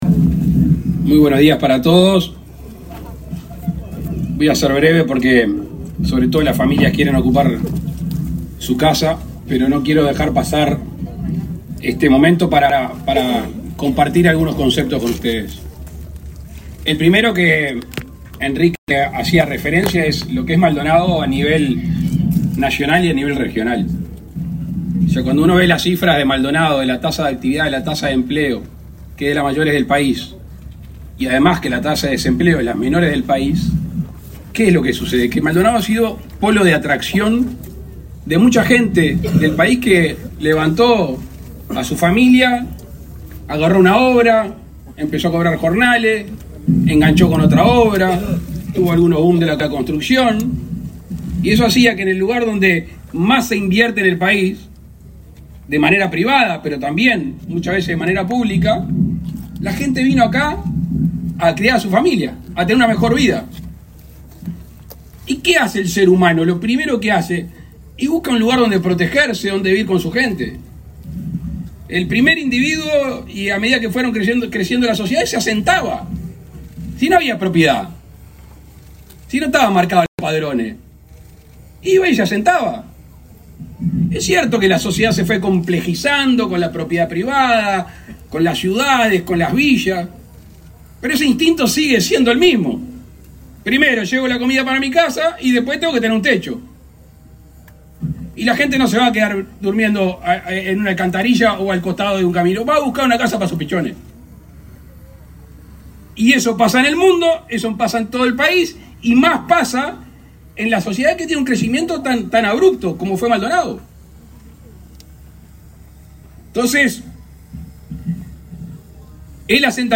Palabras del presidente Luis Lacalle Pou
Palabras del presidente Luis Lacalle Pou 21/02/2024 Compartir Facebook X Copiar enlace WhatsApp LinkedIn El presidente de la República, Luis Lacalle Pou, encabezó, este miércoles 21 en Maldonado, el acto de inauguración de viviendas, construidas para el realojo de casi 400 familias del barrio Kennedy.